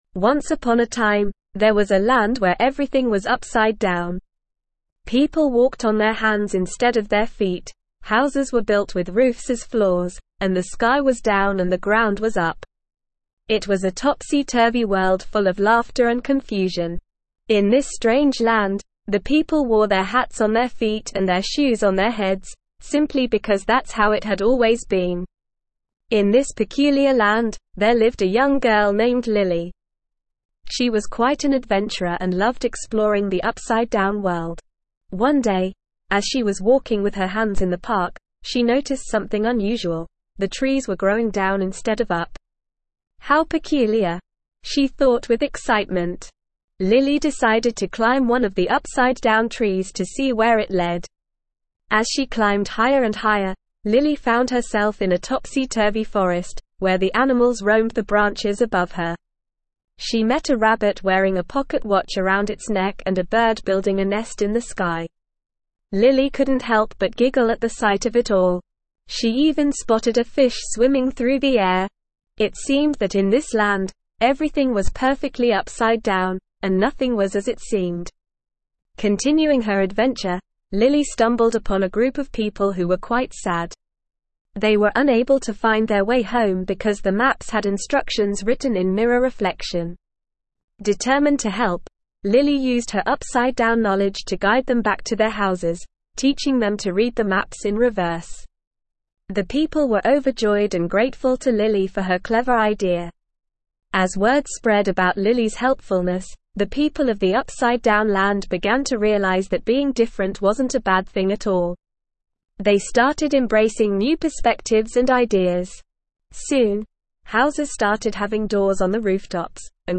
ESL-Short-Stories-for-Kids-Advanced-NORMAL-Reading-The-Land-Where-Everything-is-Upside-Down.mp3